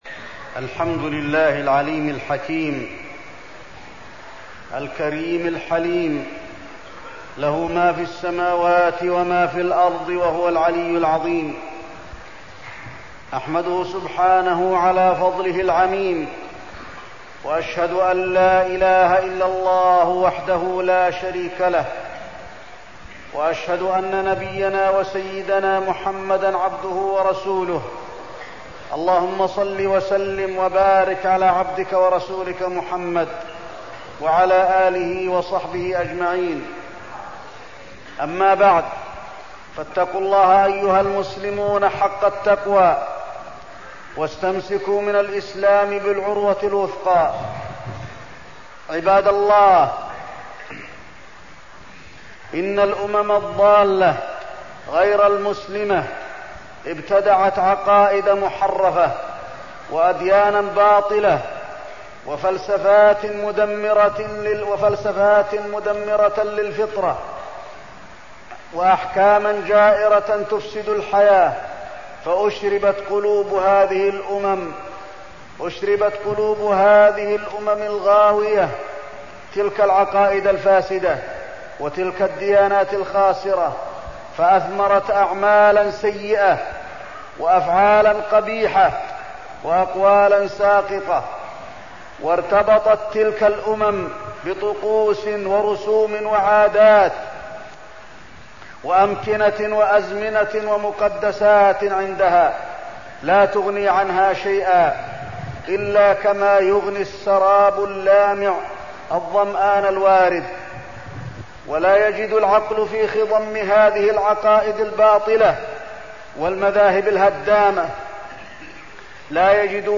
تاريخ النشر ٢١ ذو القعدة ١٤١٥ هـ المكان: المسجد النبوي الشيخ: فضيلة الشيخ د. علي بن عبدالرحمن الحذيفي فضيلة الشيخ د. علي بن عبدالرحمن الحذيفي الحج والعمرة